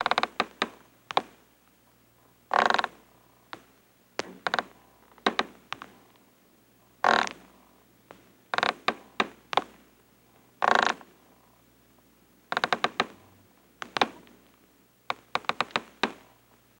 Rope Creaks, Moans And Groans